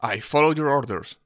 worker_ack4.wav